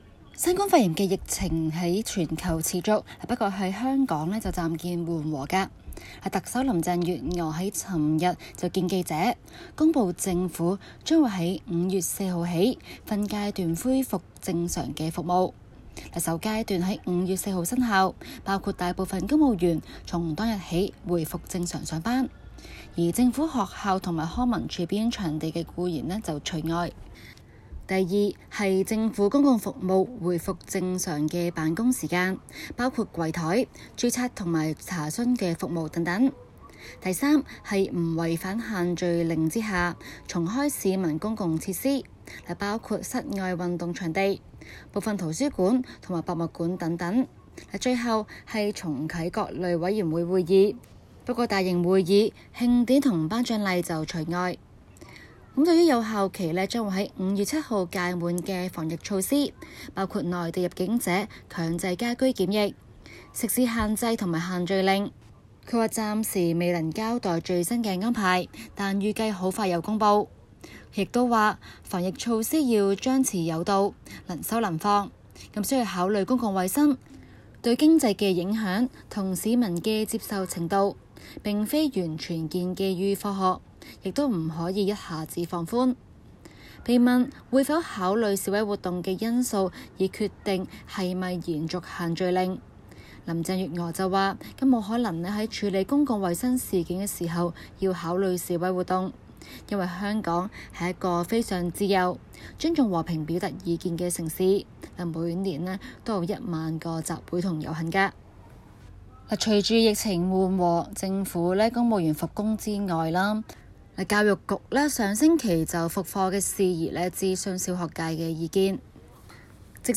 Source: PxFuel SBS廣東話節目 View Podcast Series Follow and Subscribe Apple Podcasts YouTube Spotify Download (10.45MB) Download the SBS Audio app Available on iOS and Android 今期 【中港快訊 】環節報道特區政府即將逐步解封，反映疫情的紓緩現象。